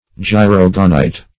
Search Result for " gyrogonite" : The Collaborative International Dictionary of English v.0.48: Gyrogonite \Gy*rog"o*nite\ (j[i^]*r[o^]g"[-o]*n[imac]t), n. [Gr. gy^ros circle, ring + go`nos fruit.]